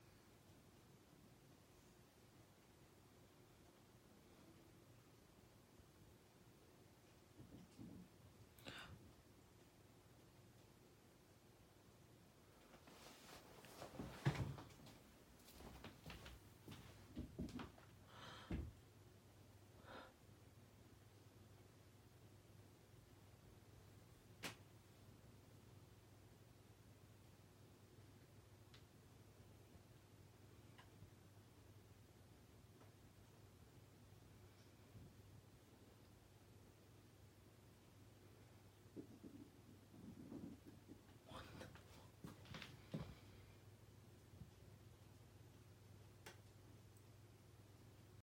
More unexplained noises all around sound effects free download
More unexplained noises all around me.